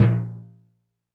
Drums_K4(45).wav